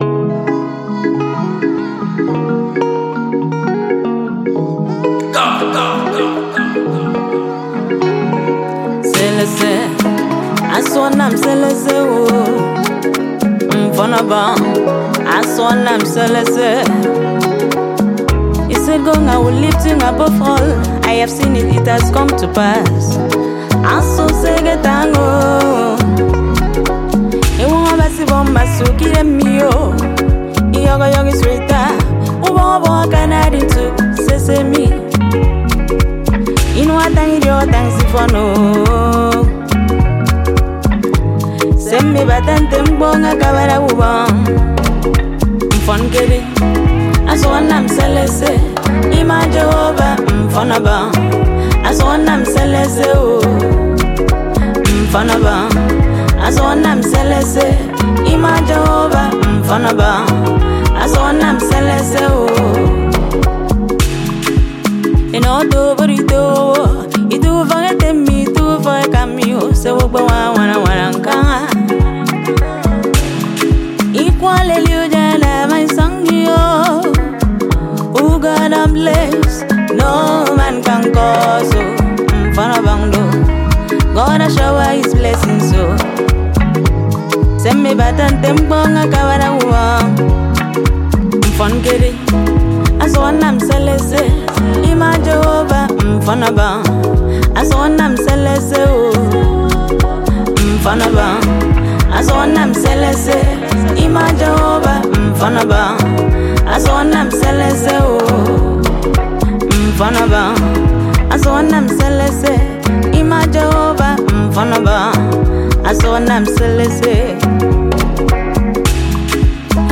gospel song
and it’s an Afro-Gospel banger!